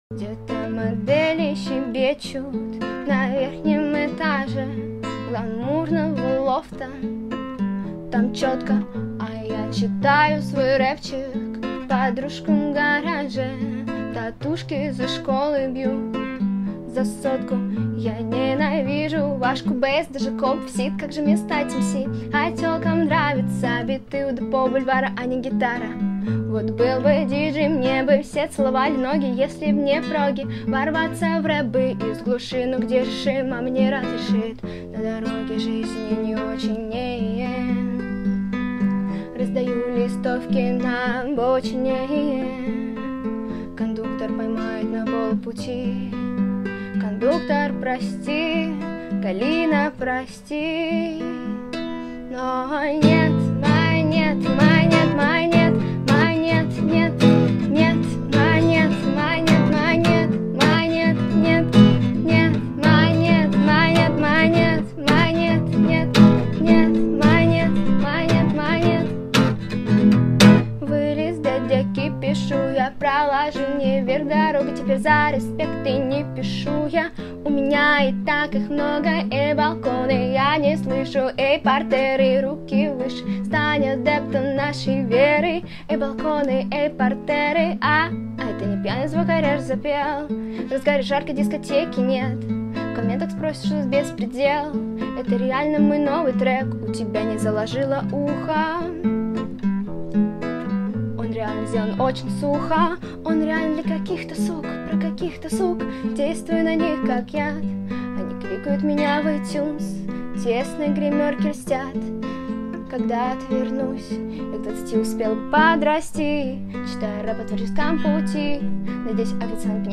В первом очень голос приятный...
Давно не слышал песен под одну гитару!